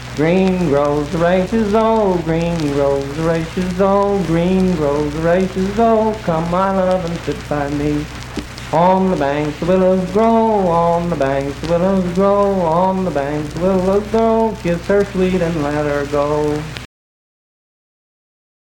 Unaccompanied vocal performance
Dance, Game, and Party Songs
Voice (sung)
Spencer (W. Va.), Roane County (W. Va.)